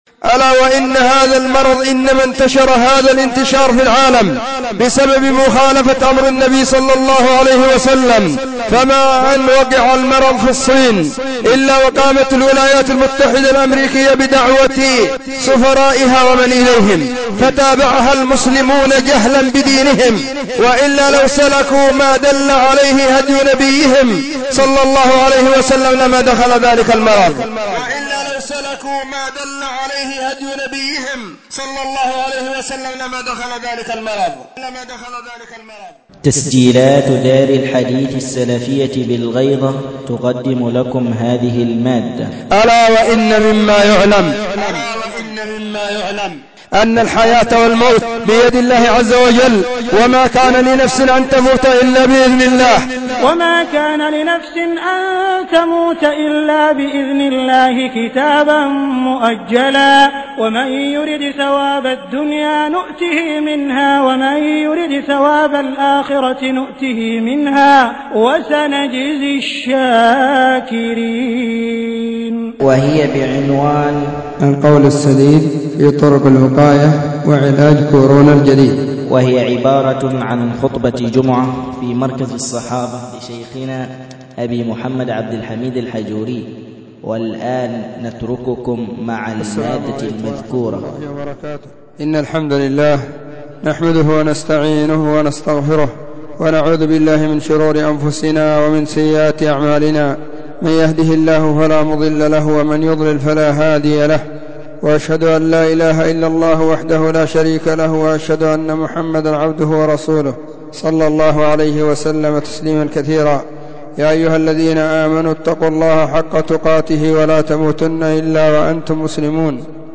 خطبة جمعة بعنوان: القول السديد في طرق الوقاية وعلاج كورونا الجديد.
📢 وكانت في مسجد الصحابة بالغيضة، محافظة المهرة – اليمن.